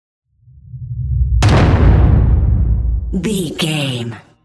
Dramatic hit explosion trailer
Sound Effects
Atonal
heavy
intense
dark
aggressive
hits